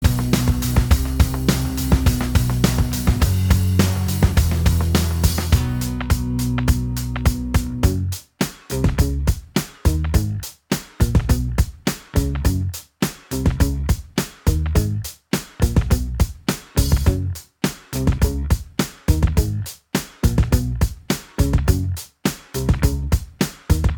Minus Guitars Rock 3:17 Buy £1.50